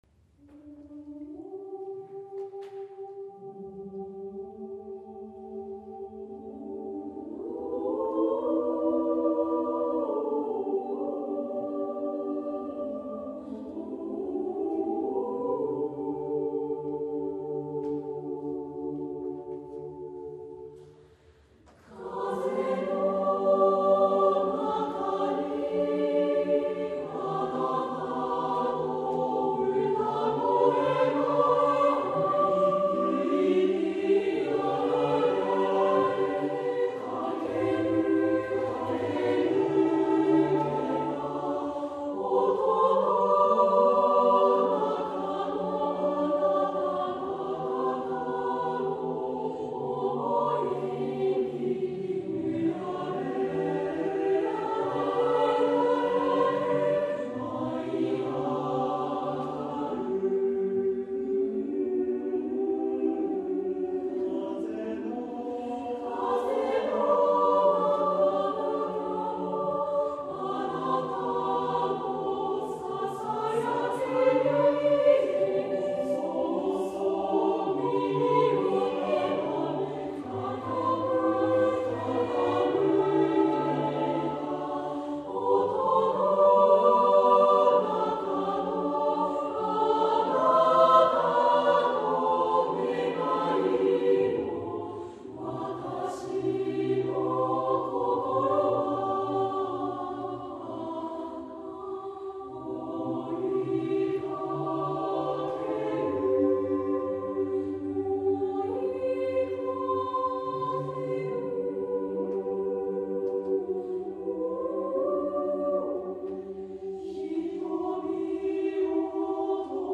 A Choral Piece
for Mixed Chorus and Piano, or a cappella
SATB, piano.